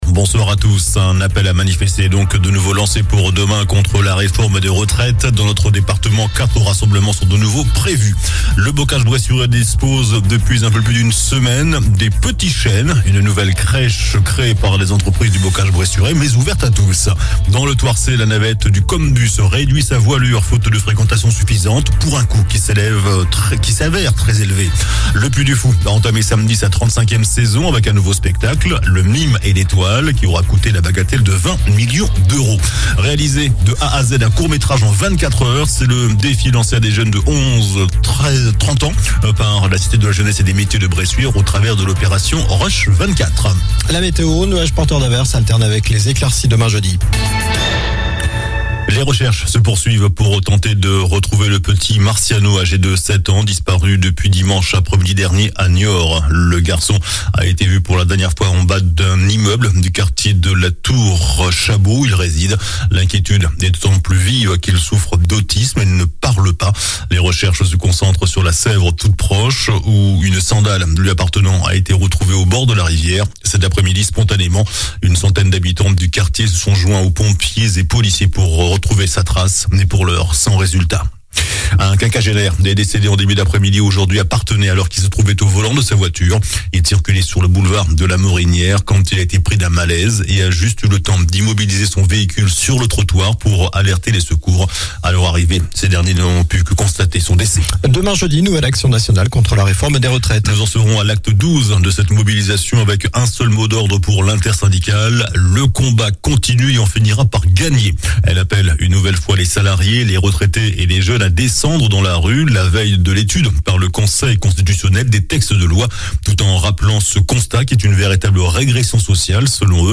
JOURNAL DU MERCREDI 12 AVRIL ( SOIR )